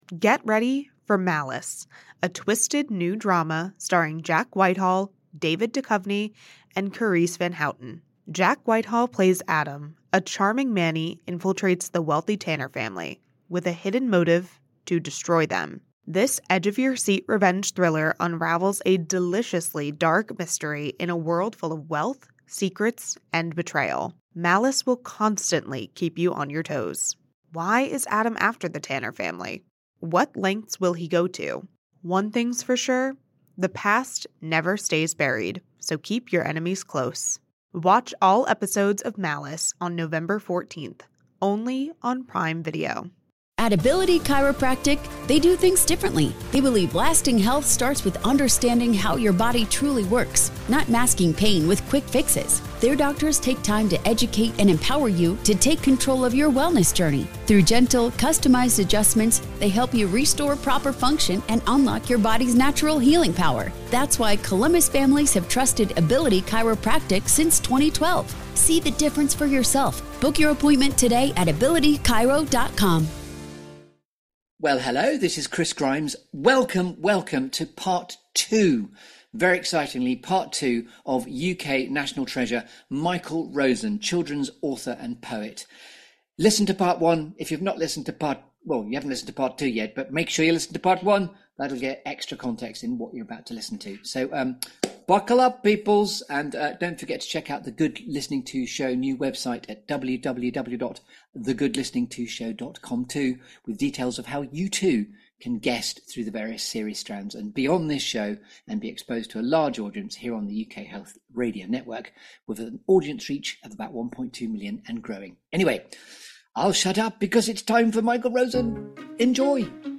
The Good Listening To Show is the Desert Island Discs of UKHR. This feel-good Storytelling Show that brings you ‘The Clearing’.